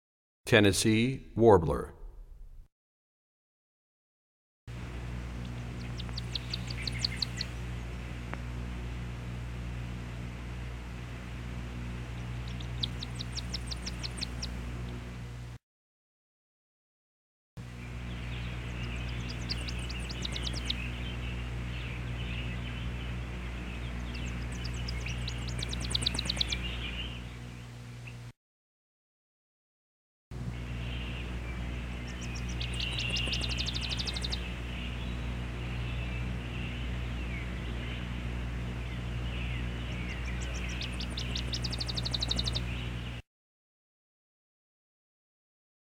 89 Tennessee Warbler.mp3